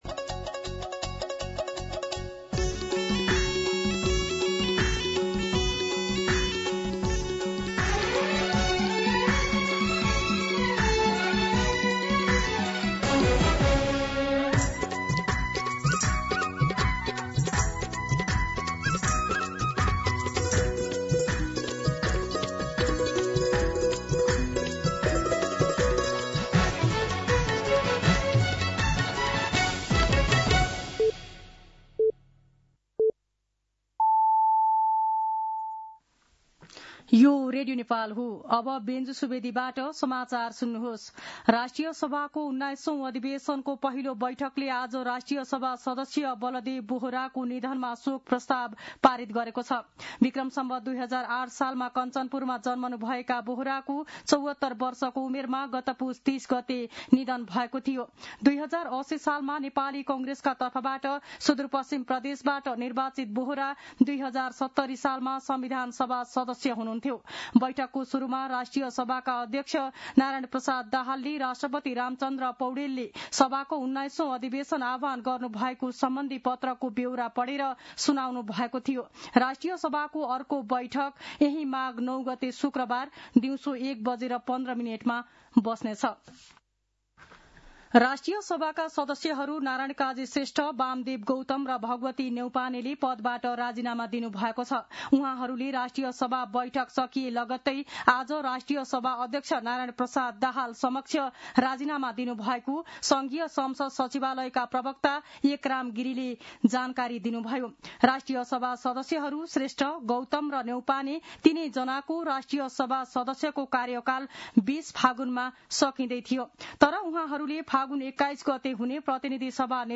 दिउँसो १ बजेको नेपाली समाचार : ४ माघ , २०८२